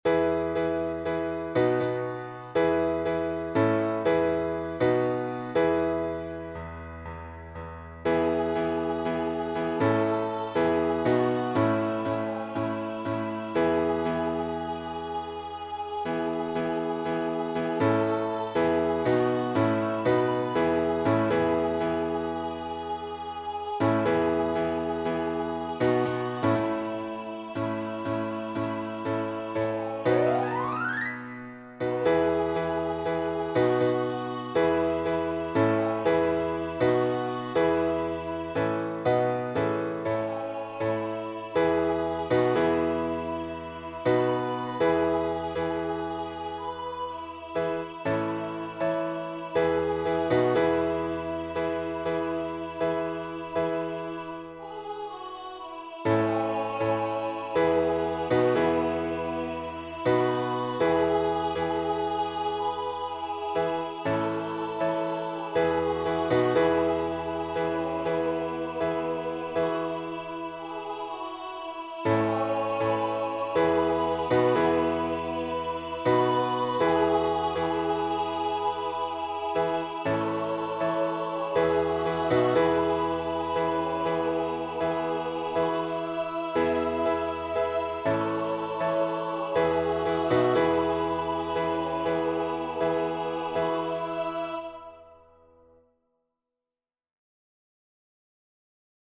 EFY style/Contemporary